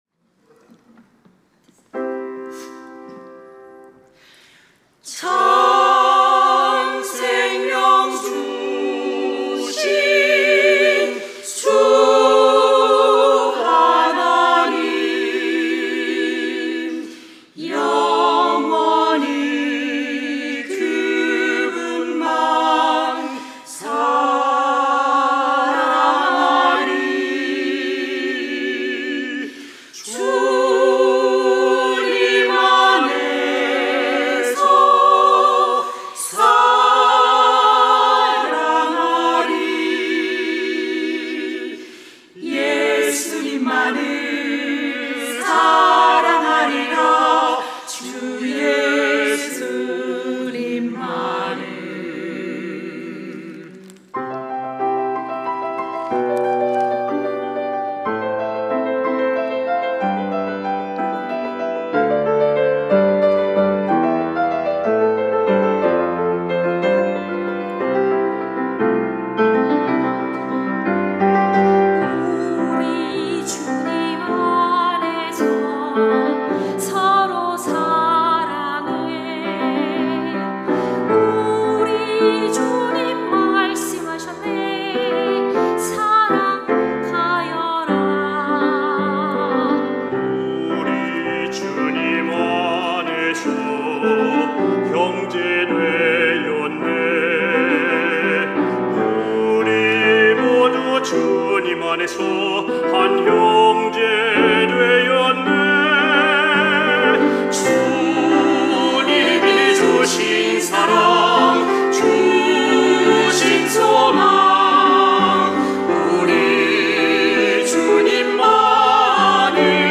특송과 특주 - 주님 안에서
솔리스트 앙상블